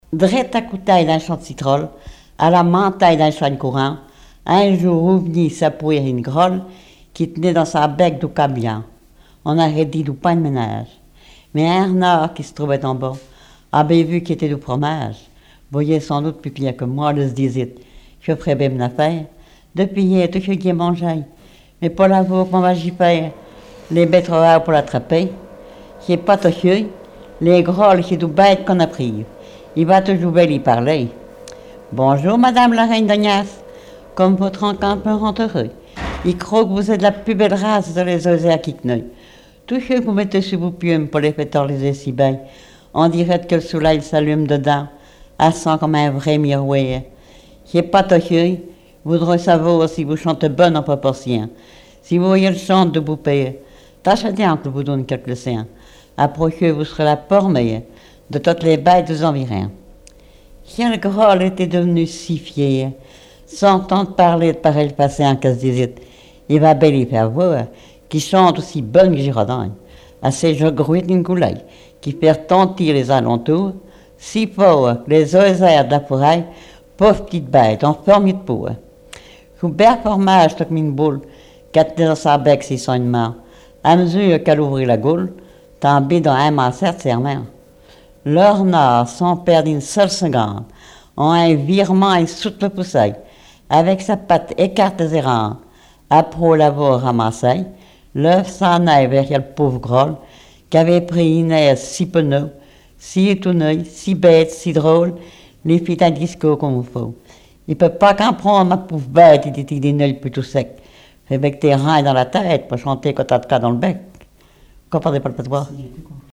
Mémoires et Patrimoines vivants - RaddO est une base de données d'archives iconographiques et sonores.
Genre fable
Catégorie Récit